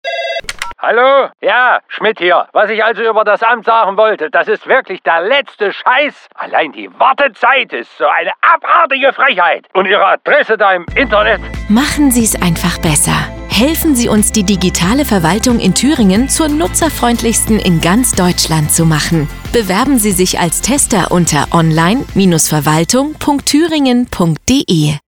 Radiospot 1 und